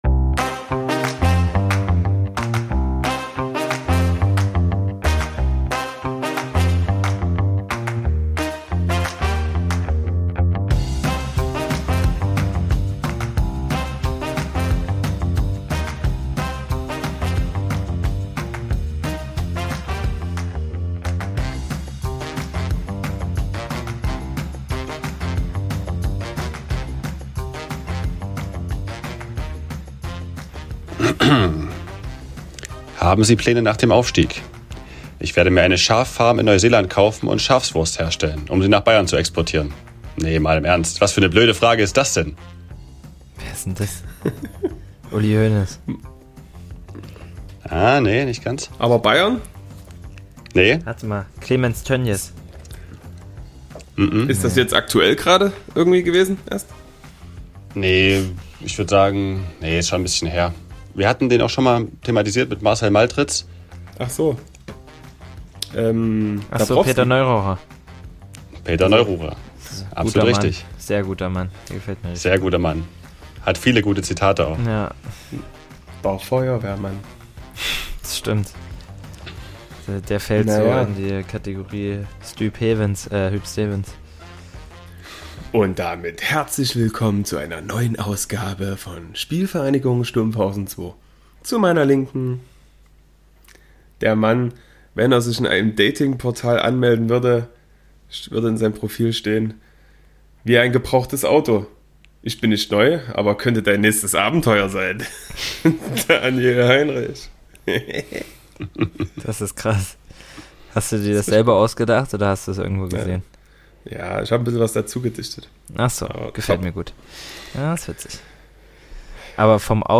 Beschreibung vor 1 Jahr Ahoi ihr arbeitsamen Stumpfis, wieder einmal könnt ihr am Donnerstag ein monotones Rauschen der drei zart geölten Stimmchen hören, die euch sanft in den Schlaf wiegen.